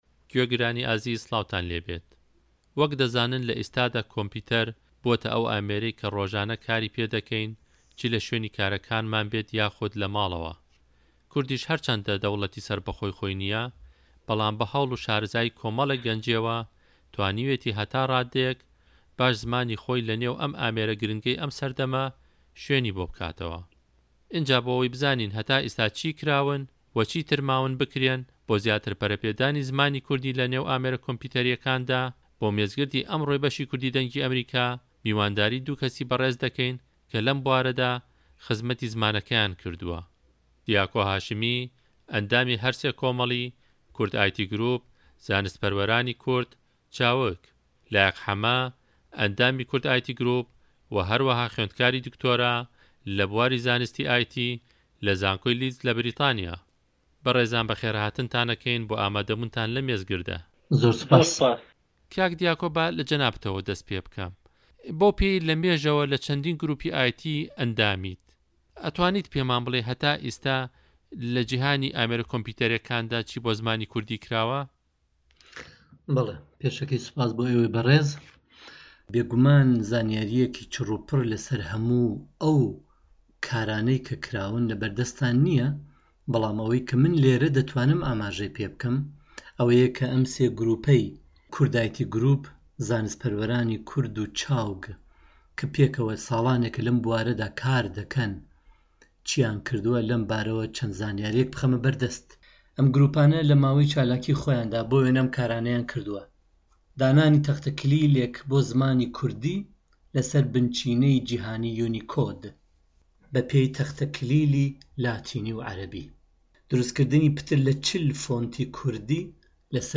مێزگرد: زمانی کوردی له‌ نێو جیهانی ئامێره‌ کۆمپـیوته‌ریـیه‌کاندا